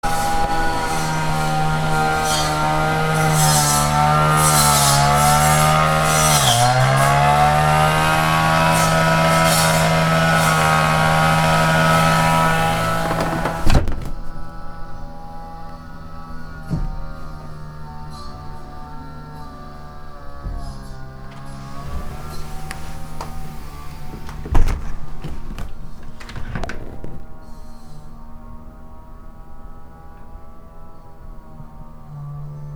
→250823_003.MP3　（SONY PCM-D100、320kbps、33秒、ステレオ）
家の15m程の距離で稼働する草刈機の音を2階から聞いている。録音は最大の音を0dBになるようにレベル調整しているので大きめに録れている。再生音量は「少し離れた所で草を刈っているな」と感じるくらいにすると私の聞いていた音と同じ大きさになる。
始めの10秒は窓を全開にしている。エンジン音や硬い物に刃が当たった時の金属音がよく聞こえる。次の10秒は既存の二重ガラスの樹脂サッシを閉めた状態。窓の質量が大きいため外の音がかなり軽減されるが、まだ気になる音量。最後の10秒は今回取り付けたプラマードも閉めた状態。更に音が小さく聞こえるようになり、気にならないレベルになったことが分かると思う。